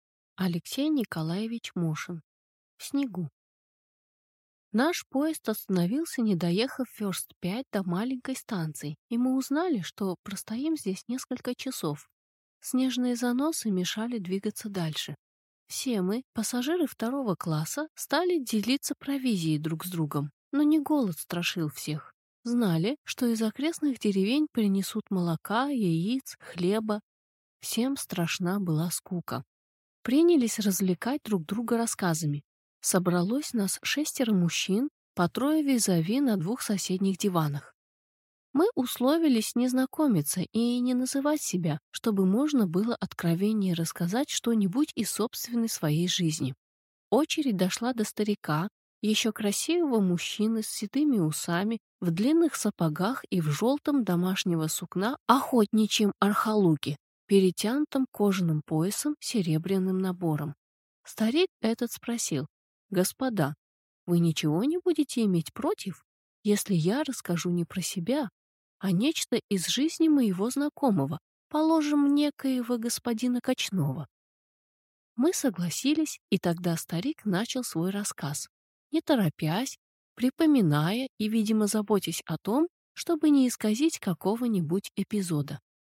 Аудиокнига В снегу | Библиотека аудиокниг